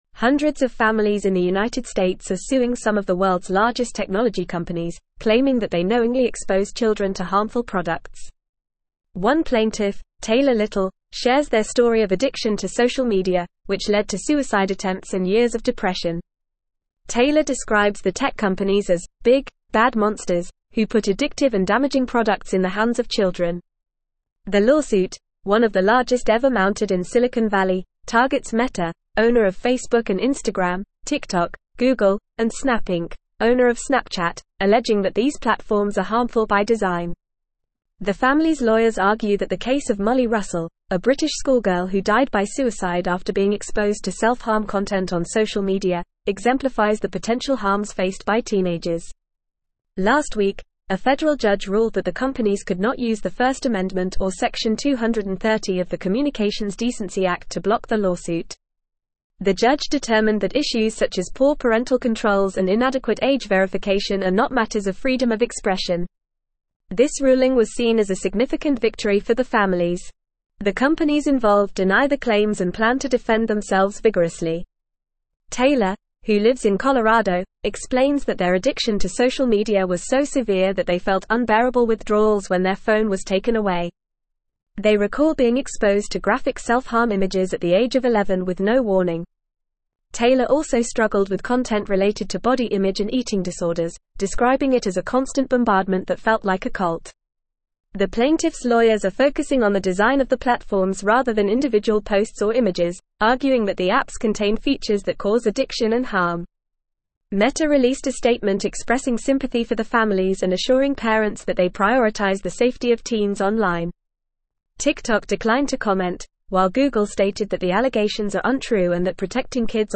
Fast
English-Newsroom-Advanced-FAST-Reading-Tech-Giants-Sued-for-Exposing-Children-to-Harm.mp3